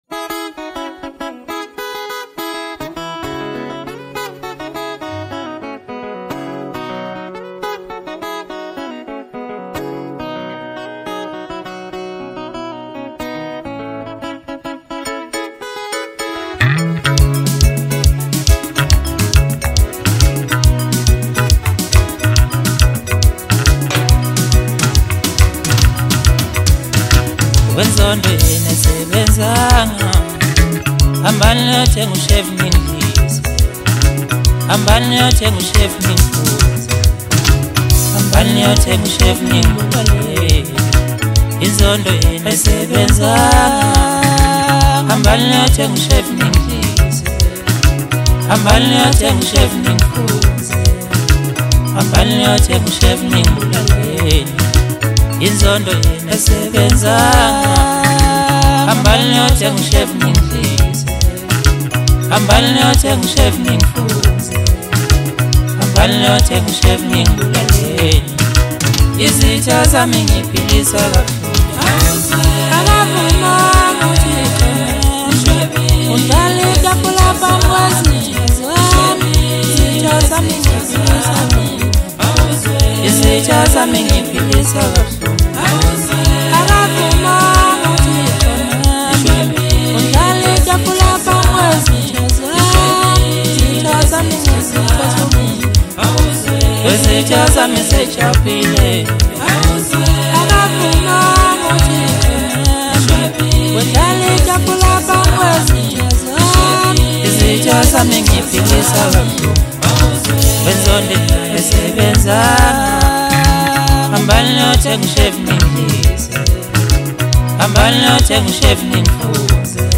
Maskandi, DJ Mix, Hip Hop
South African singer-songwriter